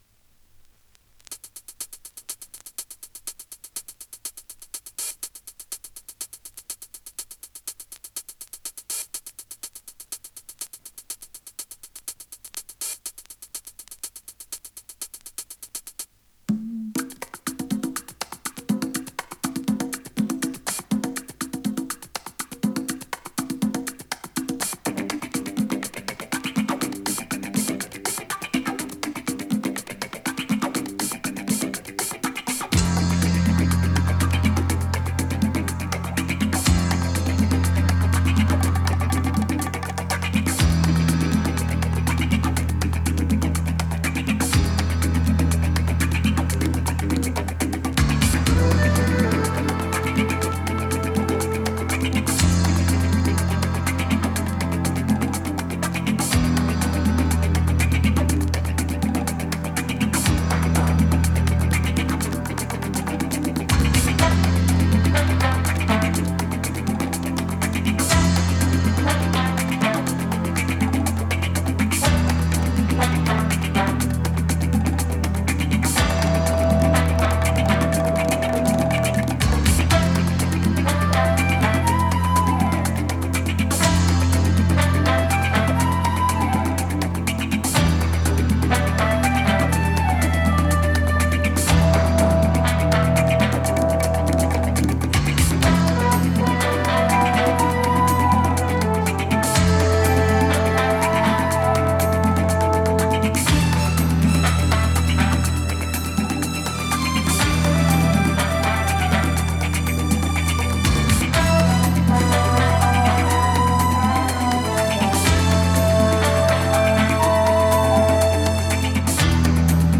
ディスコ カバー
[45RPM 2version+1track 12inch]＊音の薄い部分で軽いチリパチ・ノイズ。